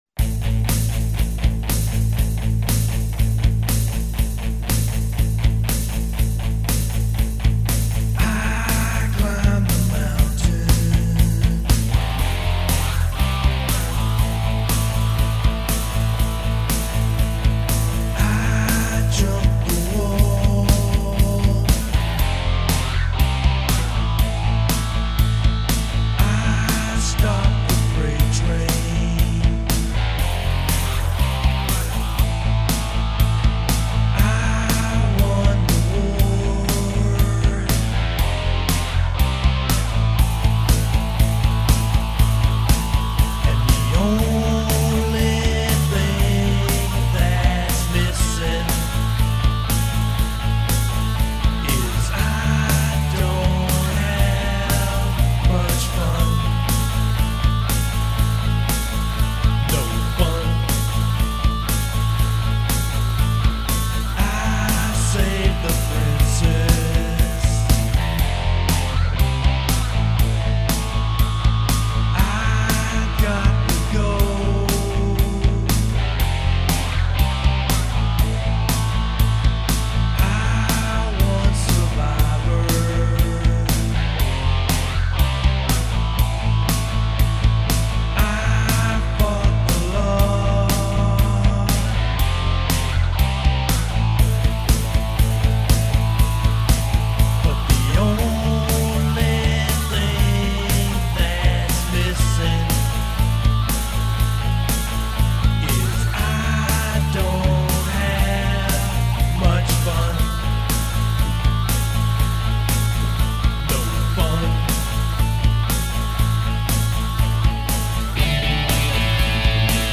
• My first original tune recorded with the ZOOM PS02